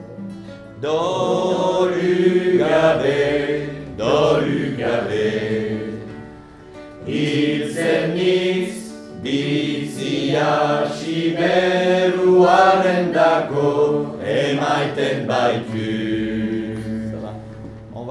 hommes_part1.mp3